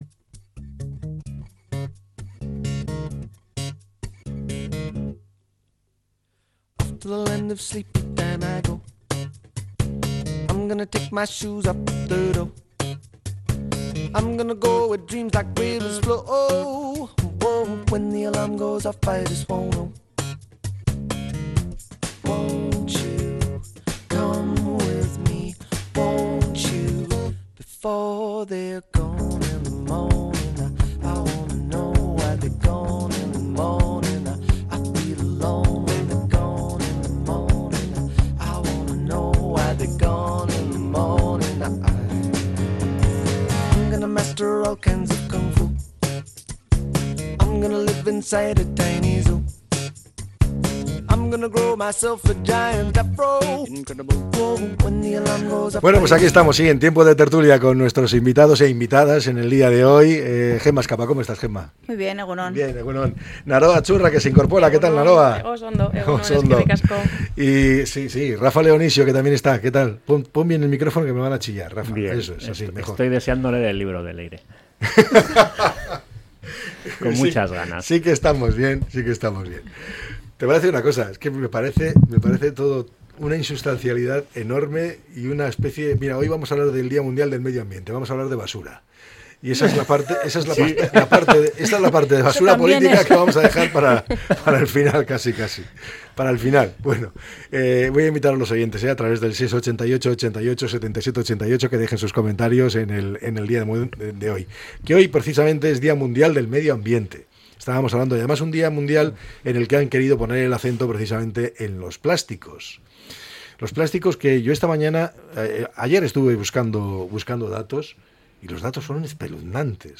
La tertulia 05-06-25.